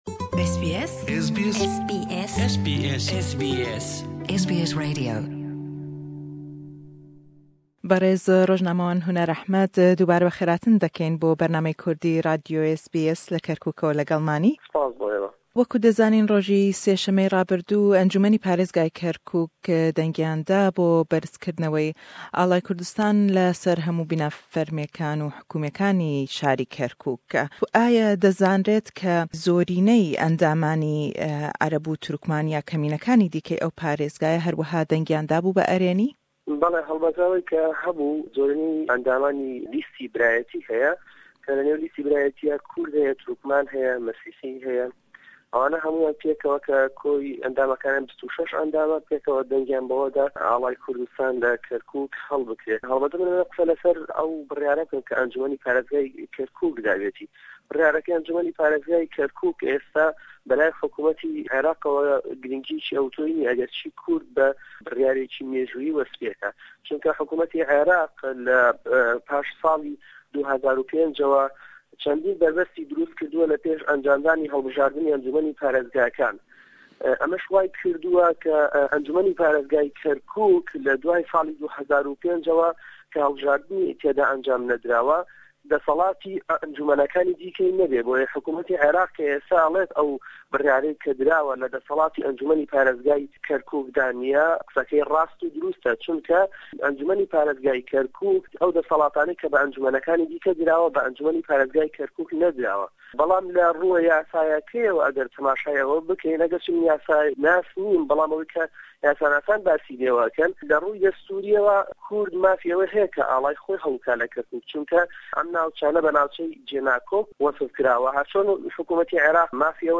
lêdwane